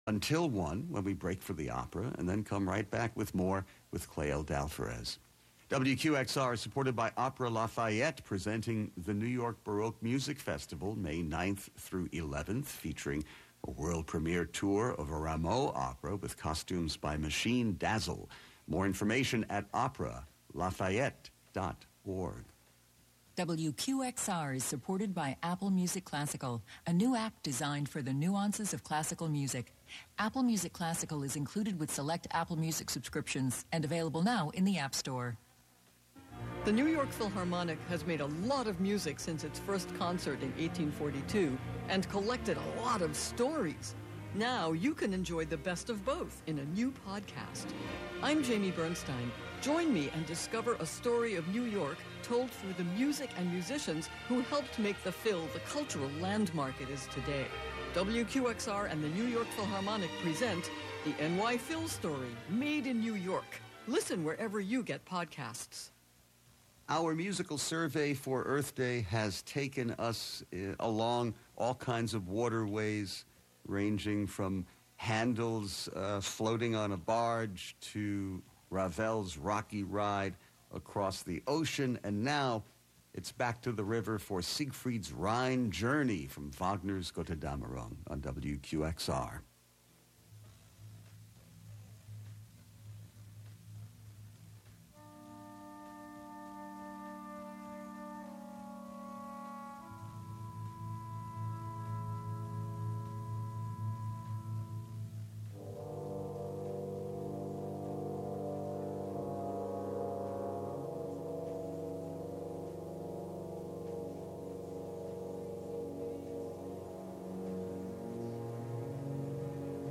"Radio Wonderland" abstracts live FM radio with laptop, electrified shoes hit with sticks, and a computer-hacked steering wheel (from a Buick 6). "Radio Wonderland" software creations include The Reshuffler (deploy slices of radio on a rhythmic grid making instant techno 90 percent of the time) the Re-Esser (extract the sibilance, play those S's, T's and K's like a drum machine,) and Anything Kick (morph slowed-down radio into a bass drum to shake the dance floor).